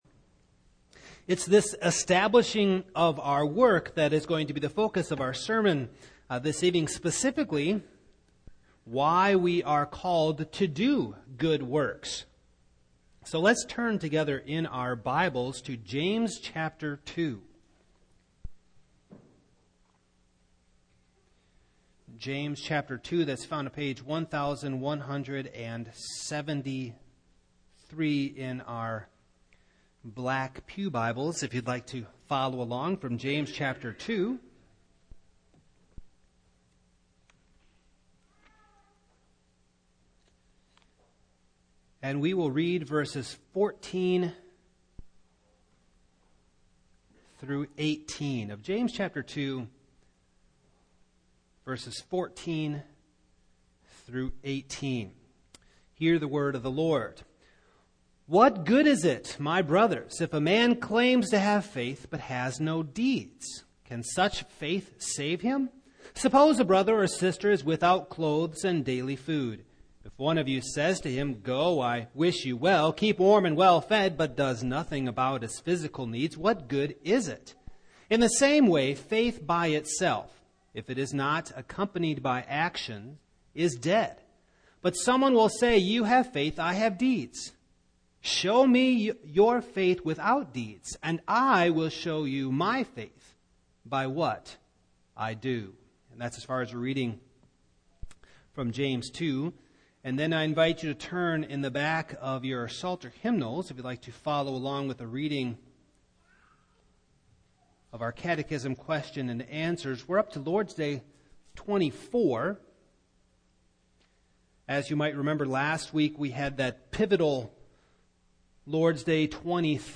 Passage: James 2:14-18 Service Type: Evening